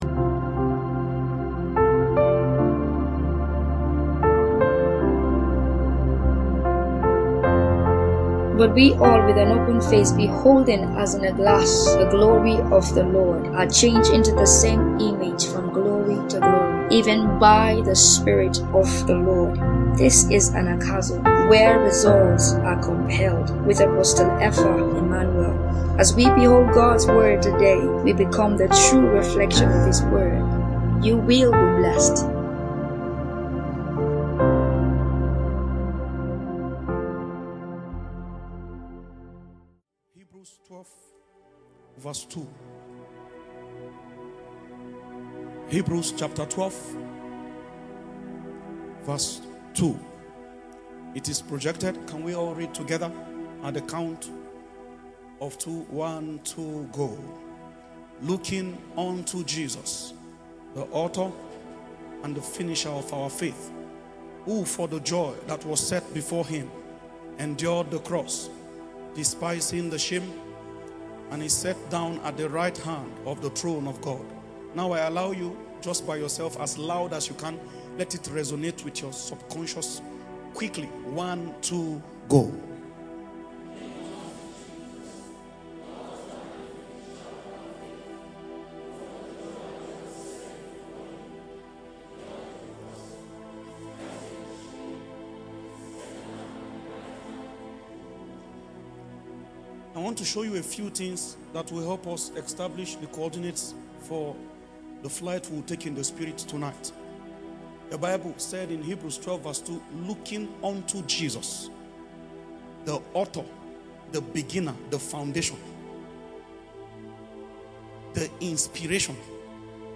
Sermon | Anagkazo Mission International | Anagkazo Mission International